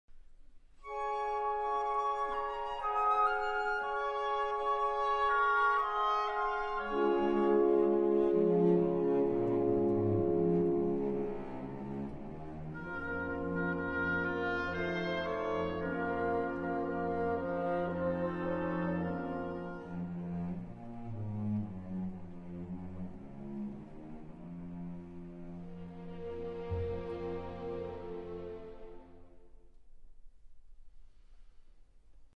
(96/24) Stereo  14,99 Select